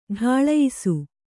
♪ ḍhāḷayisu